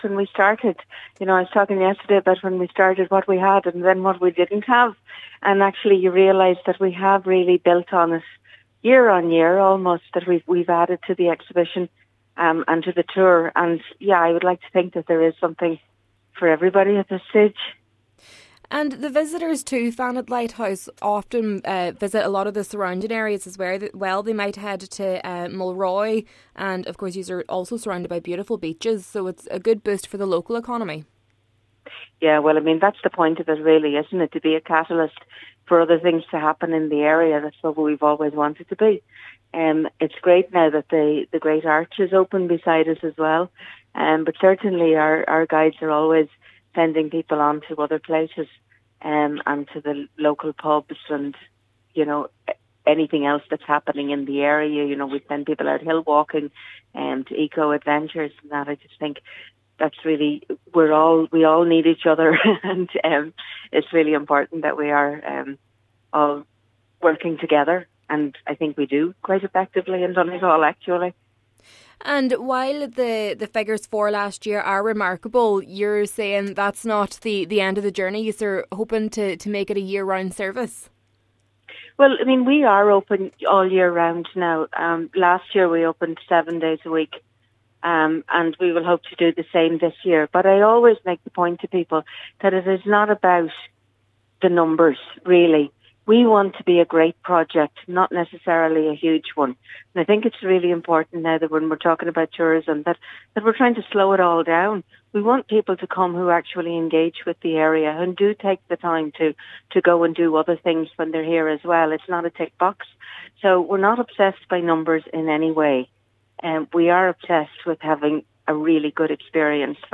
Speaking at the International Marine, Lighthouse Tourism and Maritime Heritage Conference, marking the 10th anniversary of the Great Lighthouses of Ireland